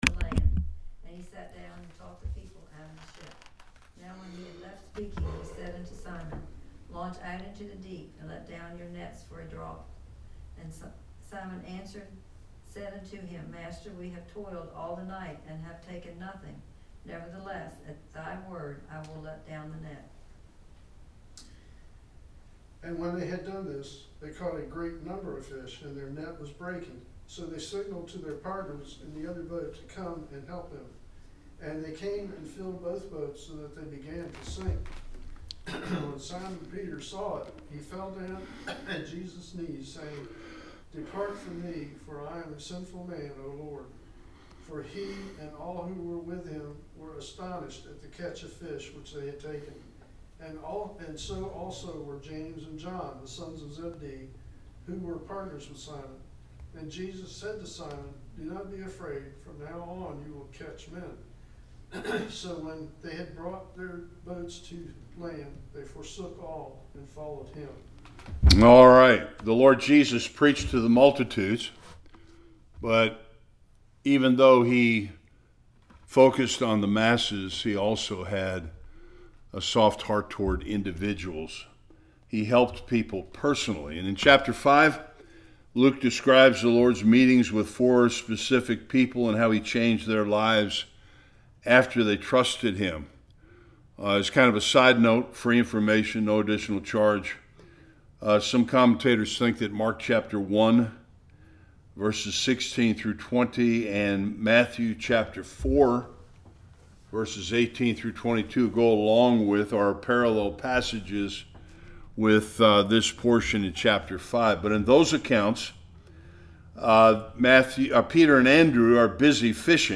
1-11 Service Type: Bible Study Peter and his partners get a fishing lesson from the Lord.